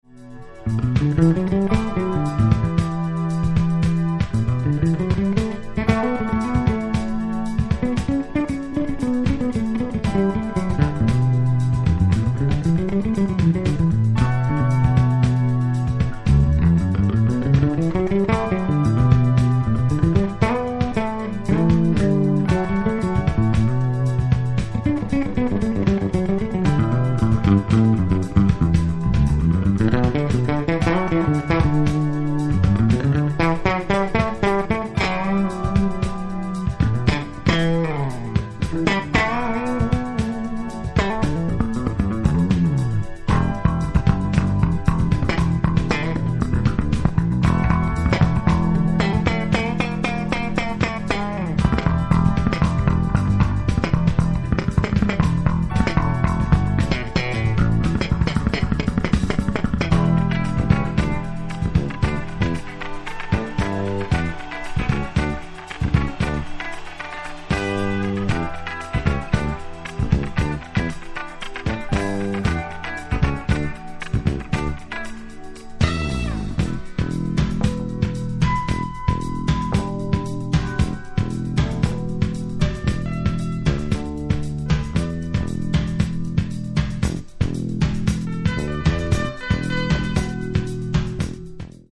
Austrian jazz / AOR / funk / pop album
jazz ensemble
infectious slow-motion disco piece